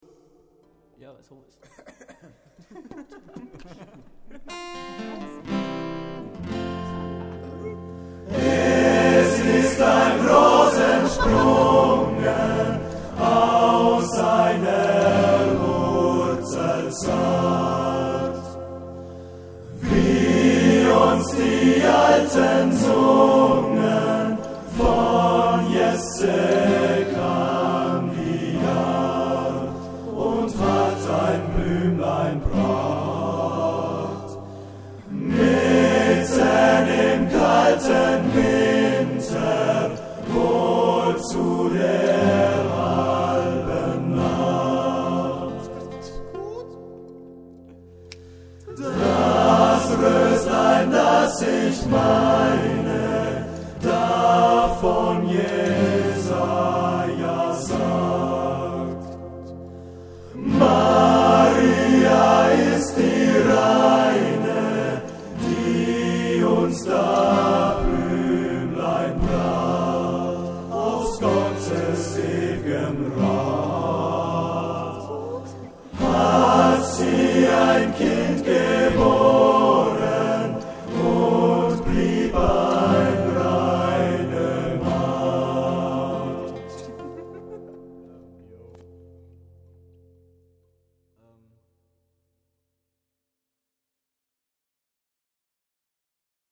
Weihnachtsweise
im Maraton Studio zu Gange.